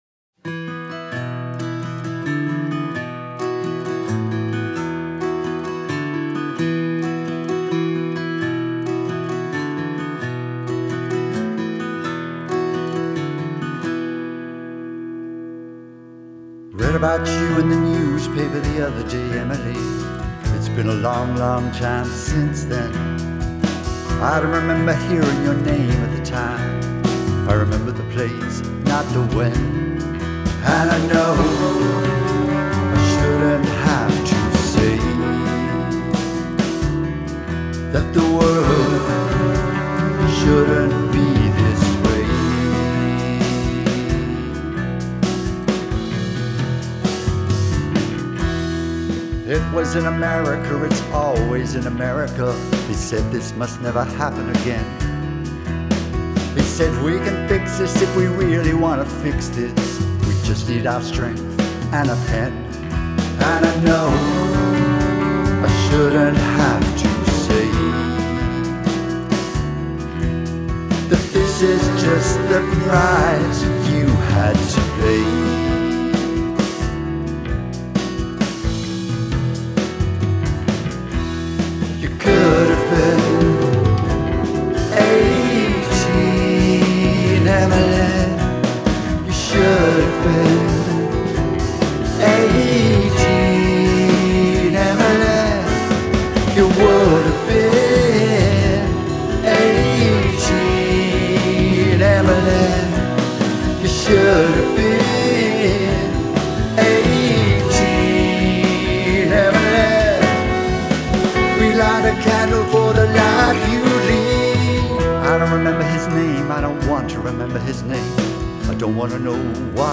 an old guy is singing